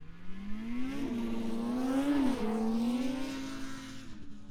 Snowmobile Description Form (PDF)
Subjective Noise Event Audio File - Run 1 (WAV)